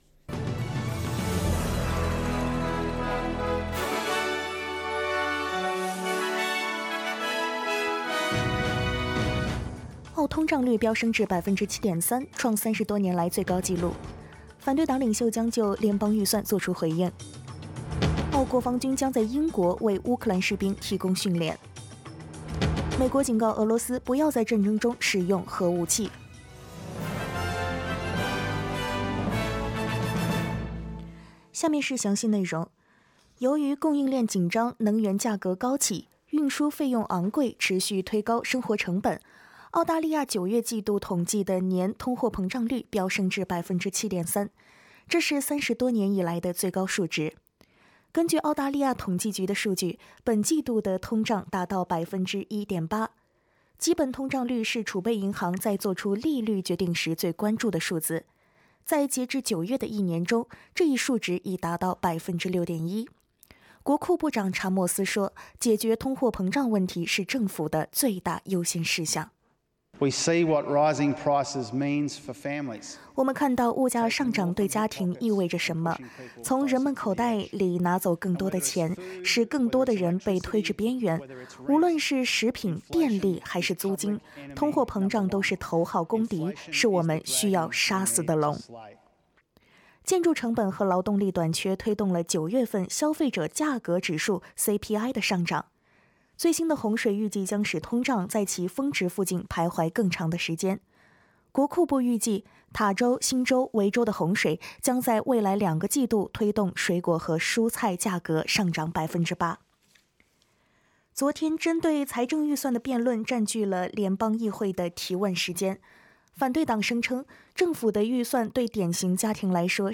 SBS早新闻（10月27日）